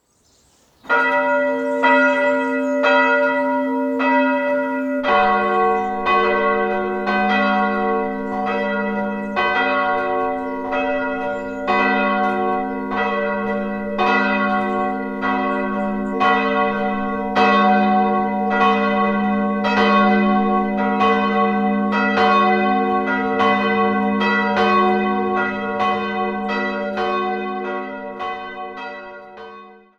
Die Störmthaler Glocken